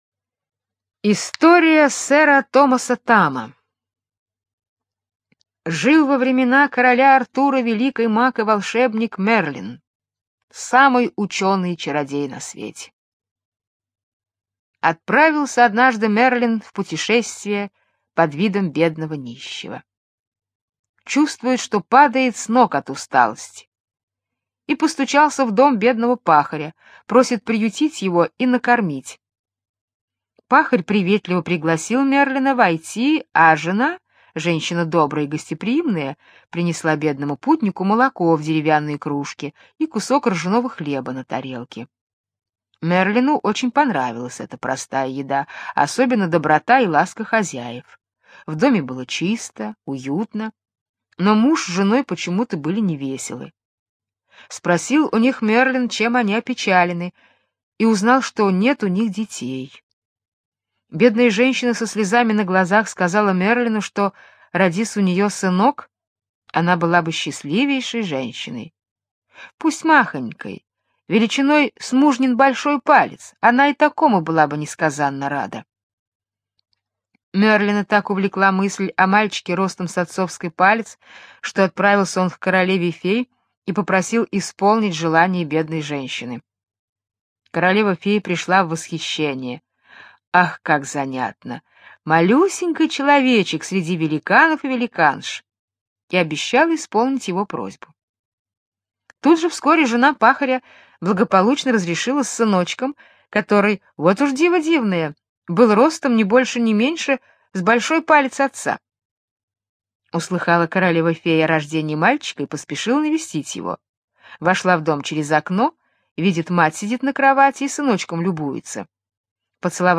История сэра Томаса Тама - британская аудиосказка - слушать онлайн